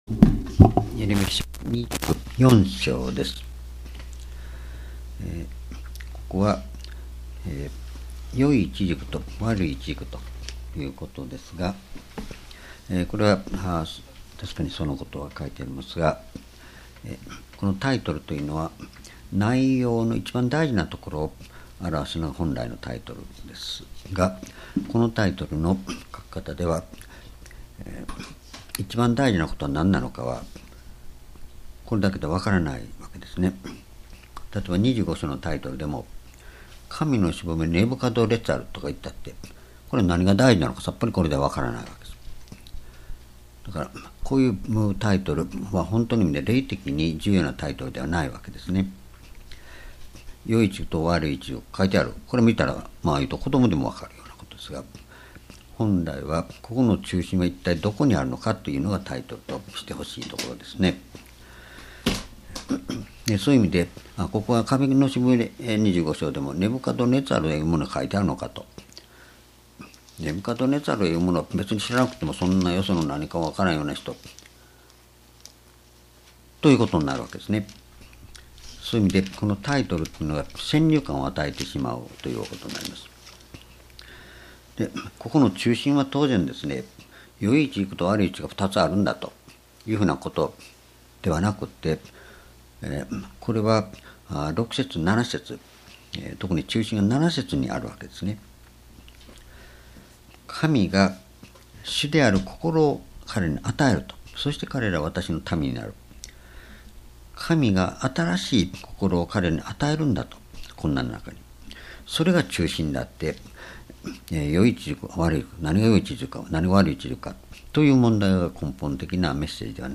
主日礼拝日時 2017年1月17日 夕拝 聖書講話箇所 エレミヤ書24 「み言葉に聞く人々への祝福」 ※視聴できない場合は をクリックしてください。